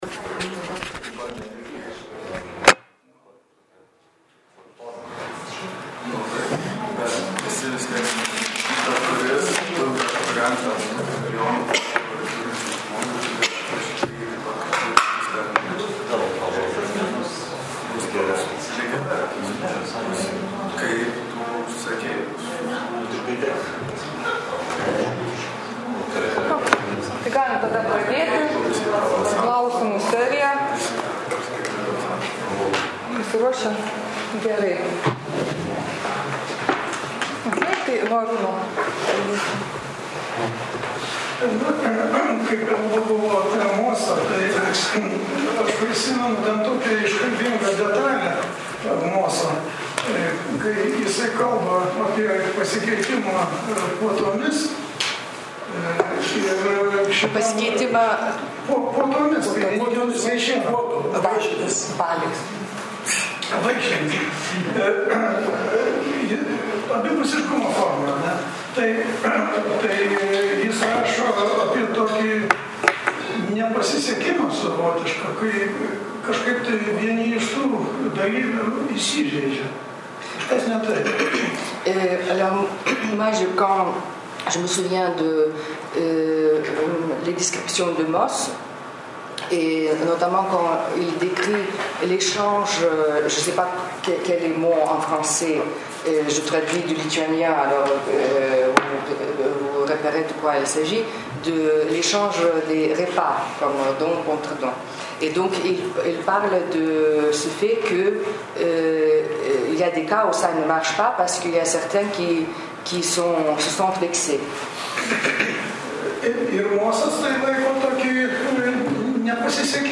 Paskaitos garso įrašas Kadangi praktikos sudaro ypatingą imanentiškumo plotmę, kuri akivaizdžiai skiriasi nuo tekstualumo tikrąją to žodžio prasme, joms analizuoti reikalinga ypatinga metodologija.